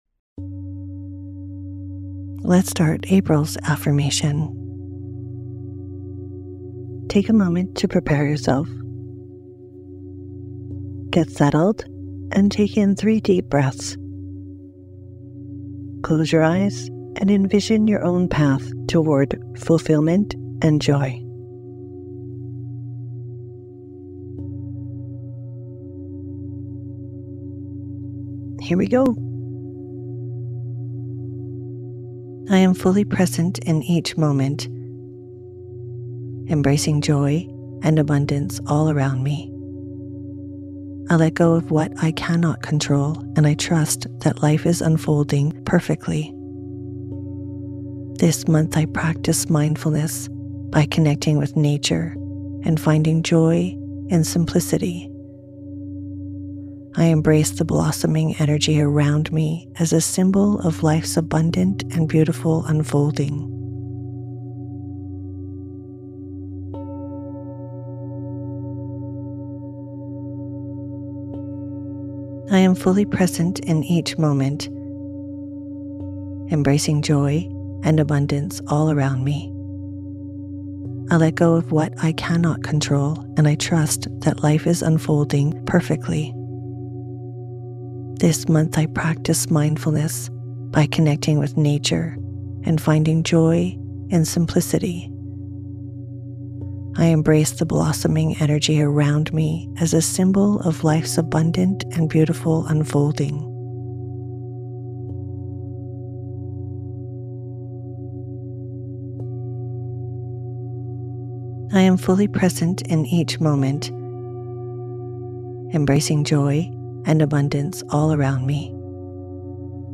Sleep versions feature the affirmation repeated three times, fostering repetition for deeper impact and greater benefits.